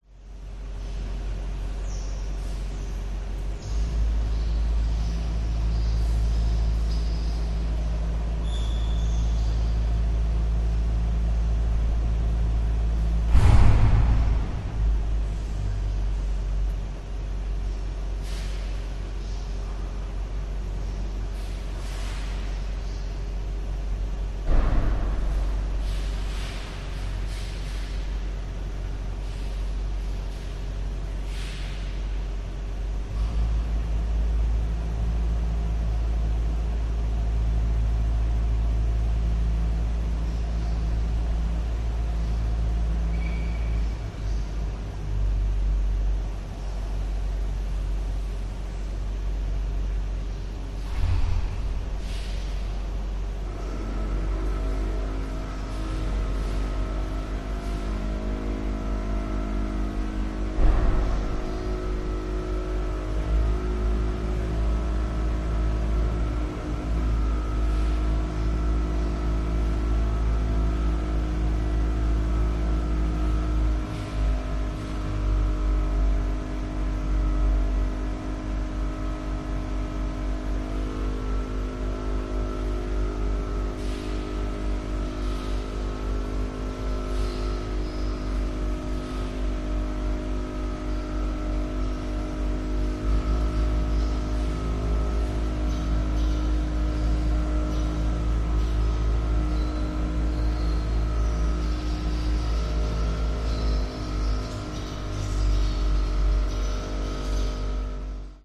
На этой странице собраны звуки городского шума: гул машин, разговоры прохожих, сигналы светофоров и другие атмосферные звуки улиц.
Шум посетителей в торговом центре